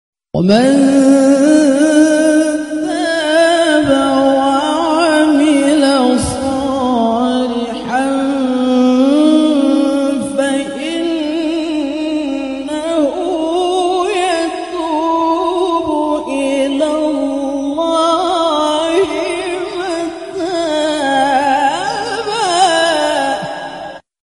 Tilawah Merdu Viral ‼surah Al Sound Effects Free Download